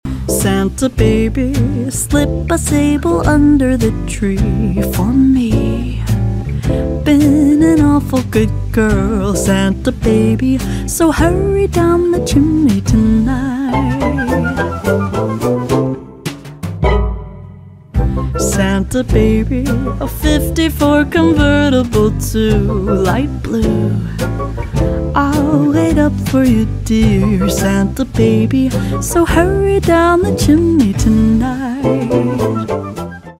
поп
спокойные